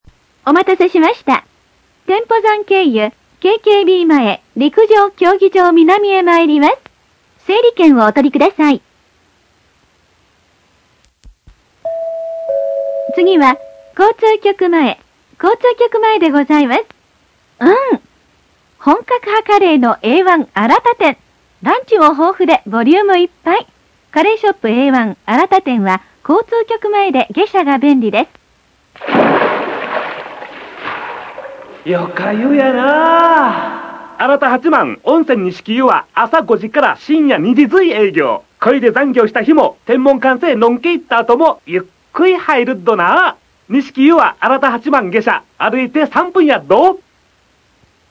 おまけ６１５０円時代の市電新型電車用案内。